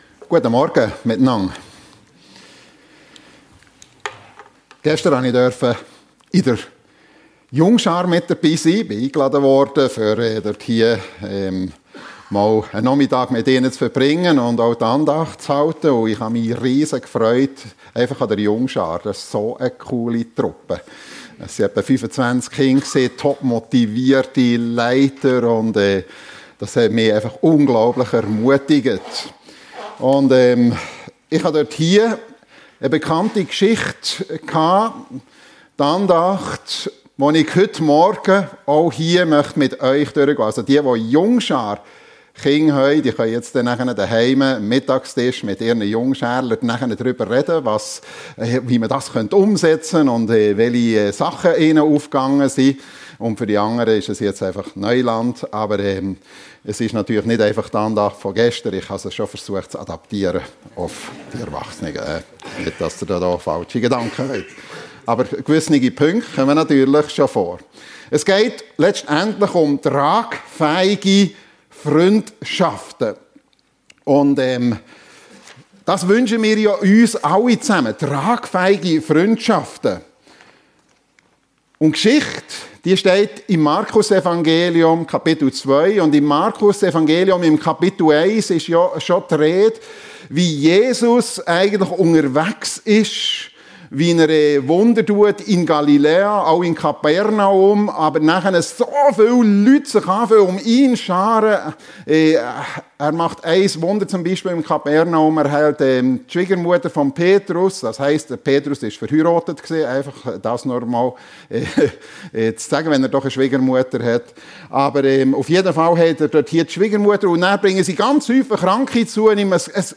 Tragfähige Freundschaften ~ FEG Sumiswald - Predigten Podcast